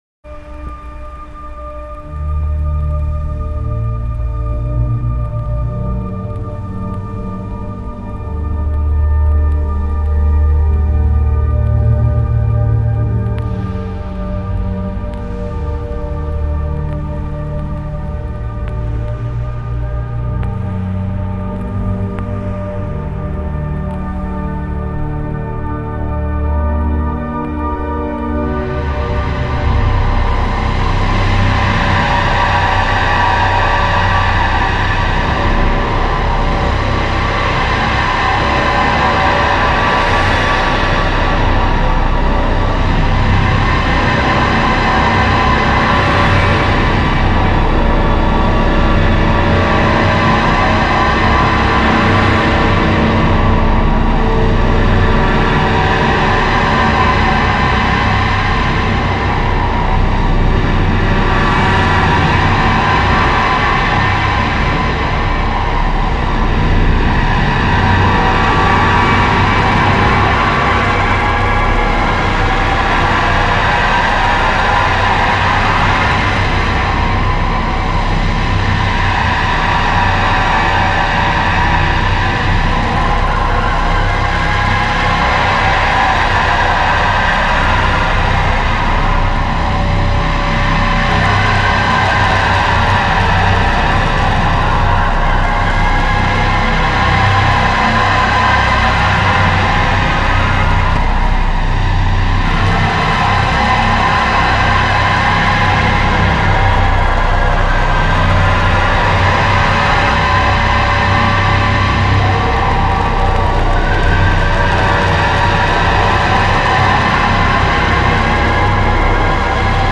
experimental sound artist